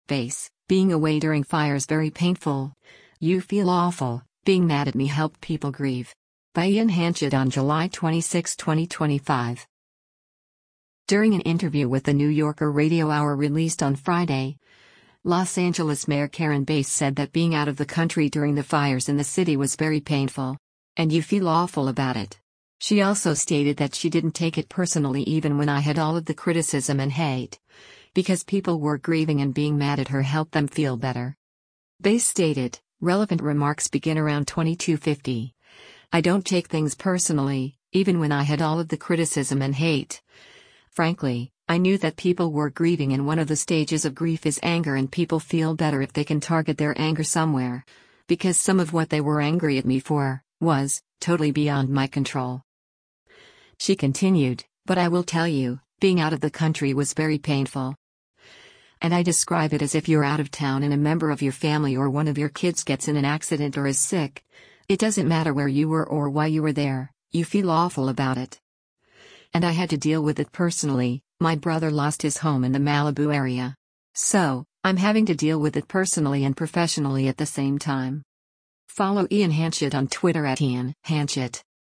During an interview with “The New Yorker Radio Hour” released on Friday, Los Angeles Mayor Karen Bass said that “being out of the country” during the fires in the city “was very painful.”